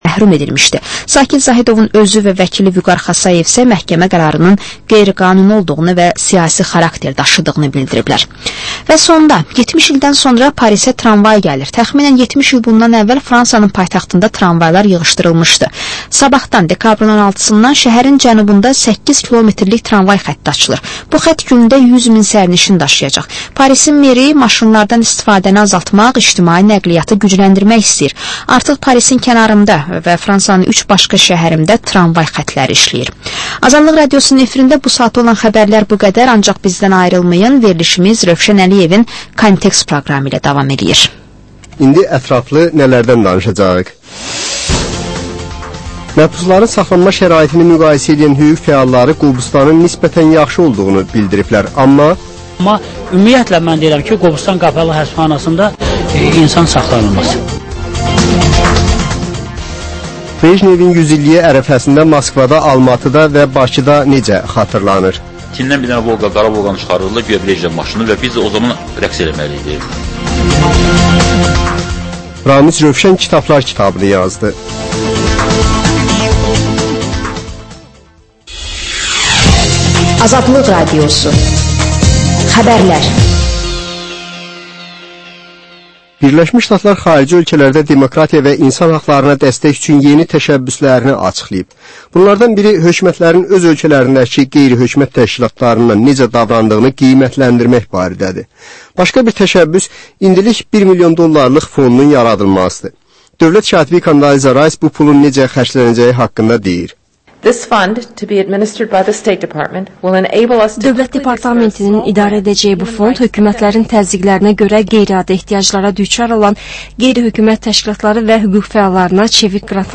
Reportaj, təhlil, müsahibə